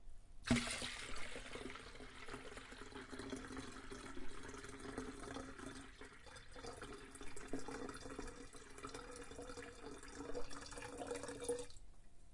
液化
标签： 设计 粮食 倾倒 流动 倾倒 液体 延迟 声音
声道立体声